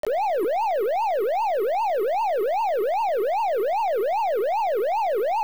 Note that there are three versions of each file - the original sound from the Pac-Man hardware, in both mono and stereo, and a generated version.
siren1.wav